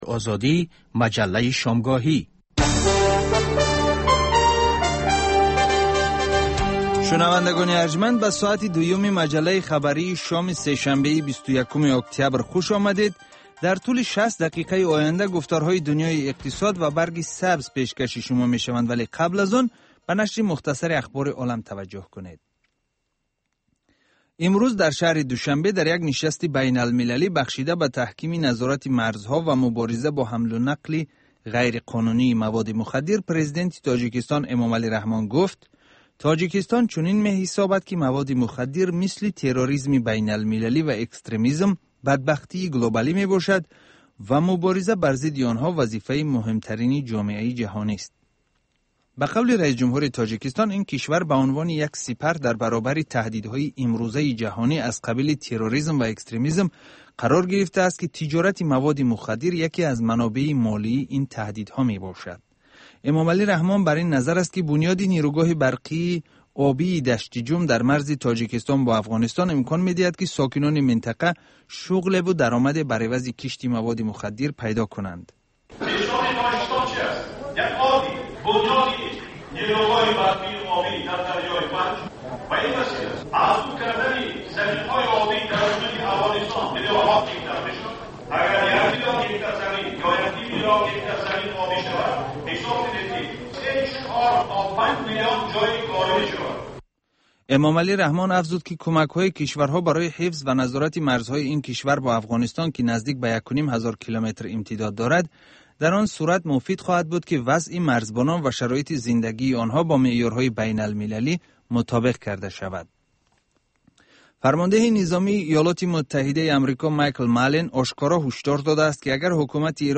Дар маҷаллаи Дунёи иқтисод коршиносон, масъулони давлатӣ ва намояндагони созмонҳои марбутаи ғайридавлатию байналмилалӣ таҳаввулоти ахири иқтисоди кишварро баррасӣ мекунанд.